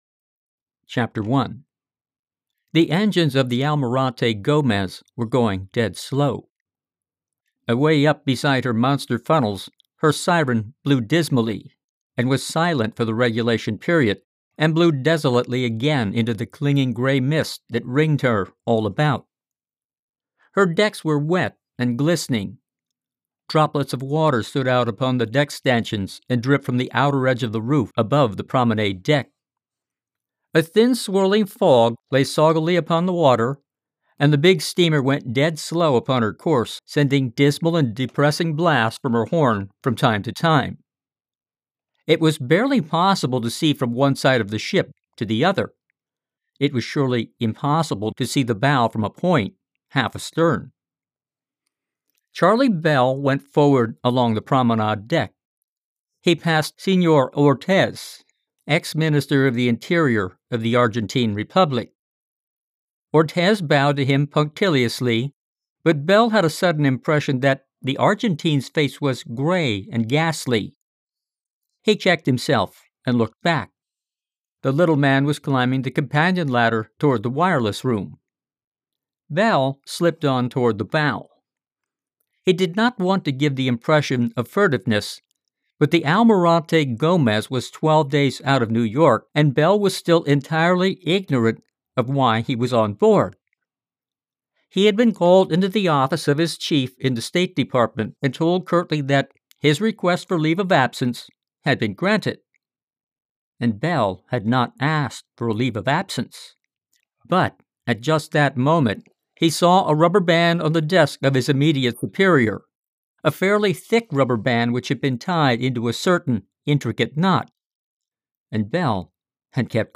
Part science fiction, part mystery, mostly thriller. Originally serialized in four parts, the Leinster's 1st novel is presented here in audiobook format.